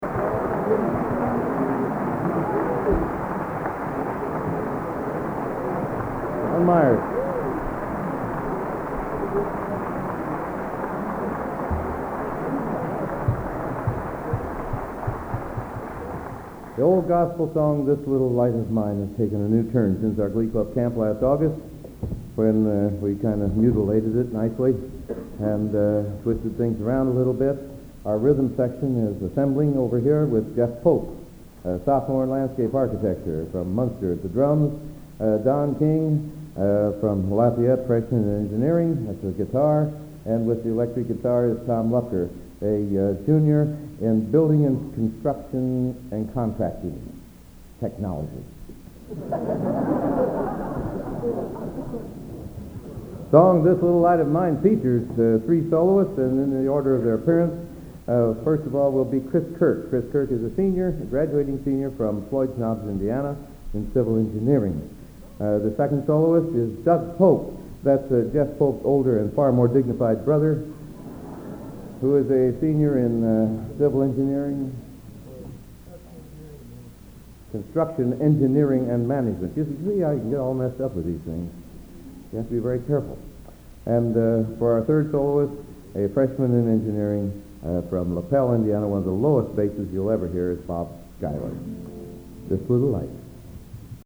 Collection: End of Season, 1979
Location: West Lafayette, Indiana
Genre: | Type: Director intros, emceeing